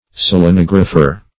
Search Result for " selenographer" : The Collaborative International Dictionary of English v.0.48: Selenographer \Sel`e*nog"ra*pher\, n. One skilled in selenography.